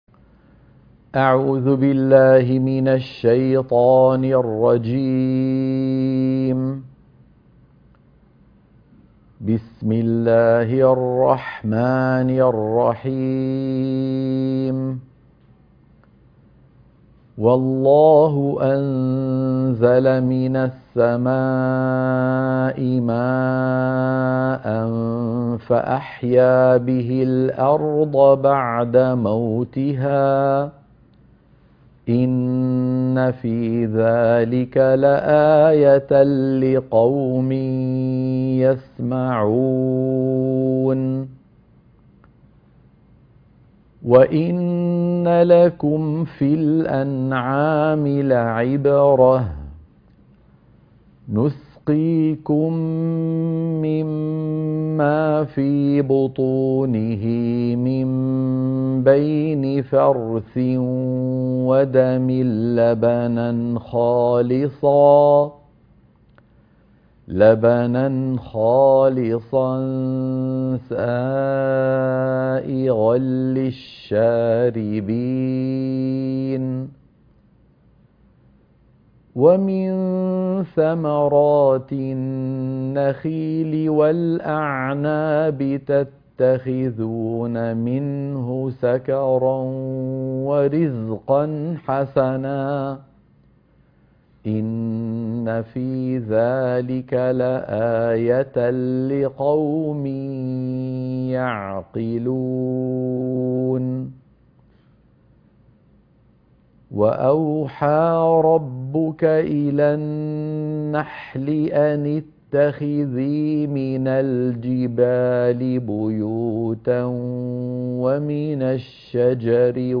تلاوة نموذجية لآيات سورة النحل من 65 ل 72 - الشيخ أيمن سويد